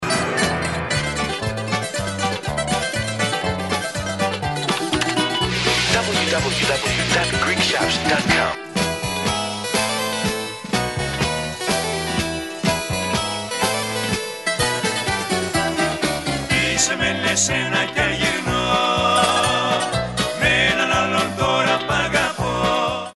non-stop Greek folk dance songs